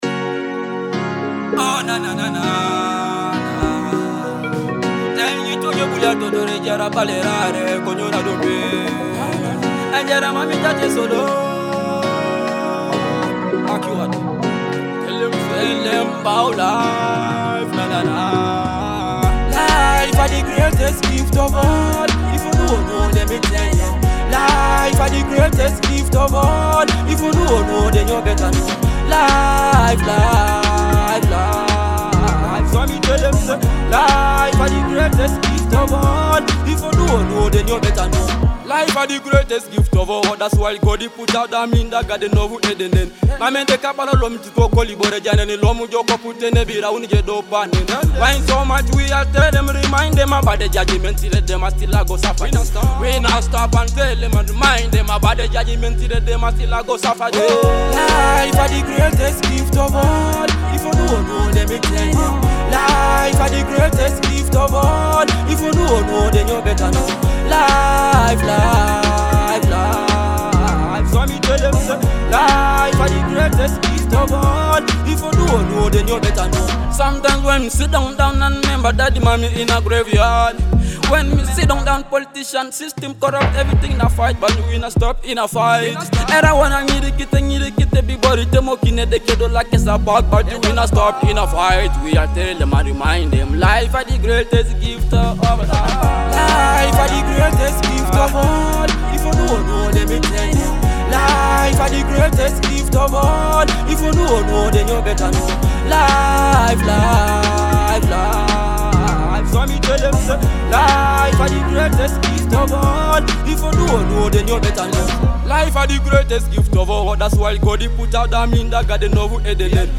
an inspiring and soulful track
powerful vocals and poignant storytelling